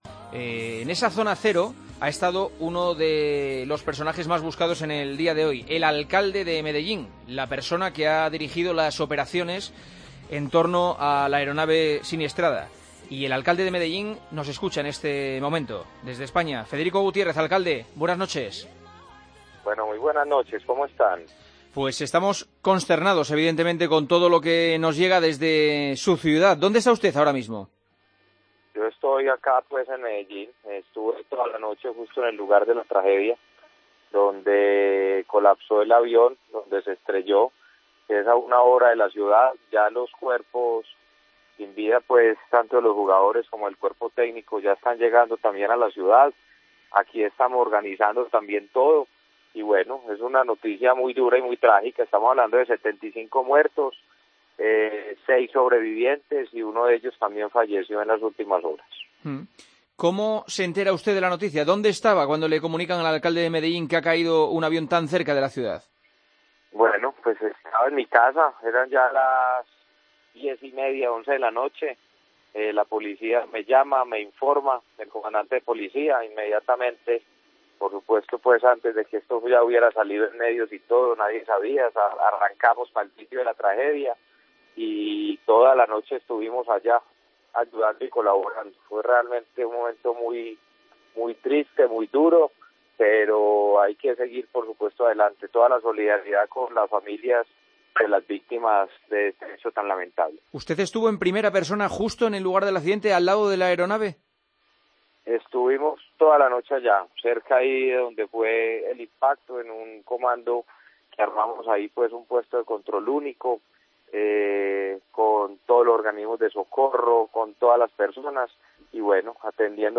El alcalde de Medellín cuenta en El Partidazo cómo se enteró de la noticia y cómo ayudó en la zona del accidente